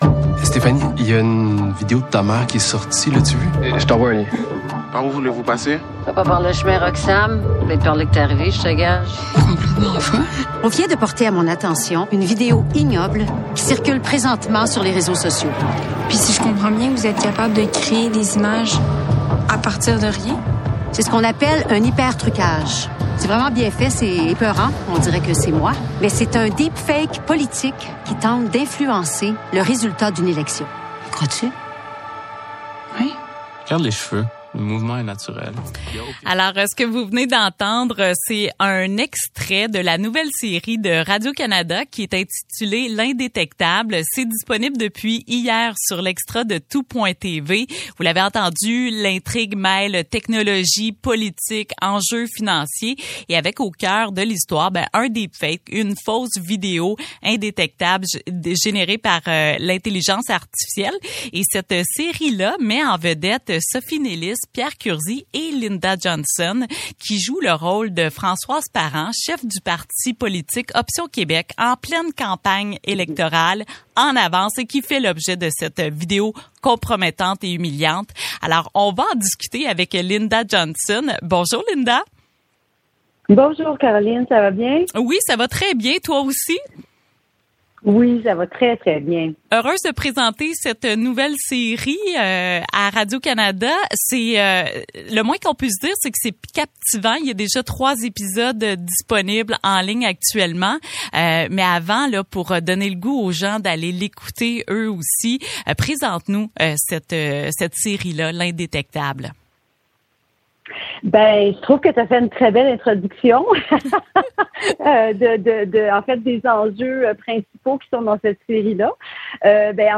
a interviewé l’actrice Lynda Johnson